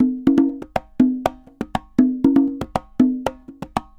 Congas_Merengue 120_2.wav